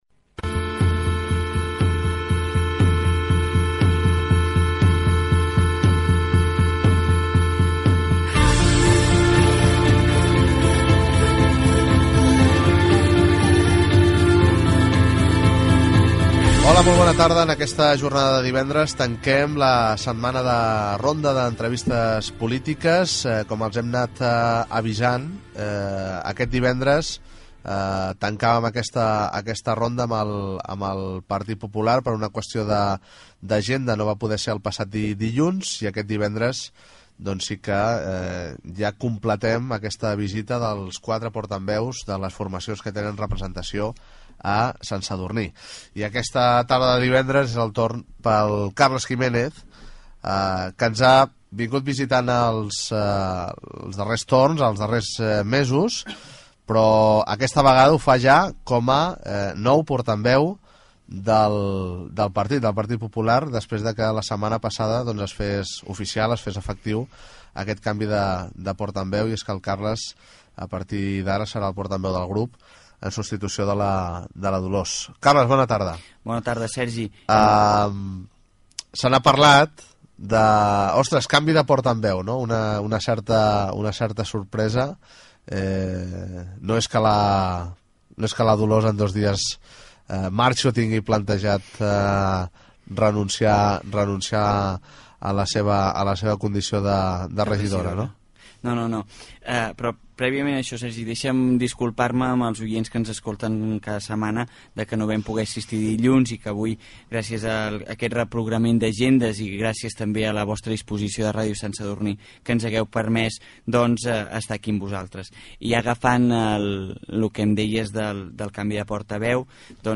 Presentació i entrevista al regidor Carles Jiménez del Partit Popular a l'Ajuntament de Sant Sadurní d'Anoia
Informatiu